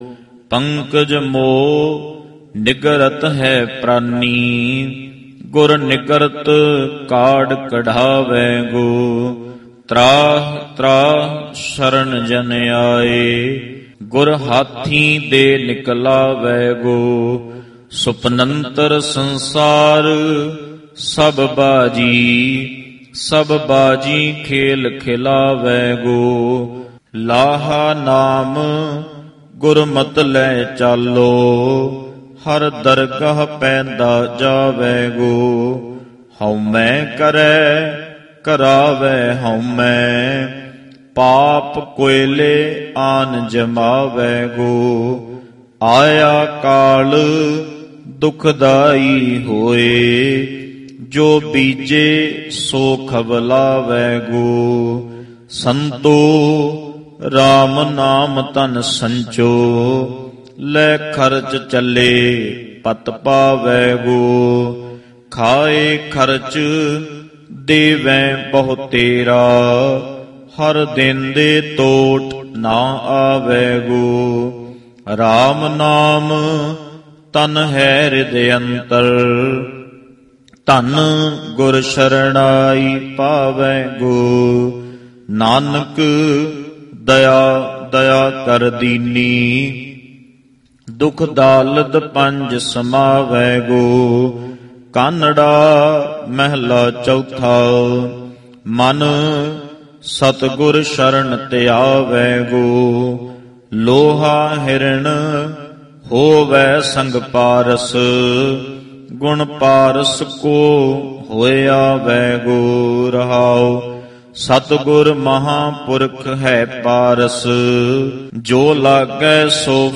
130. Sehaj Path Ang 1306 To 1316 - 4/5
Sri Guru Granth Ji Sehaj Paath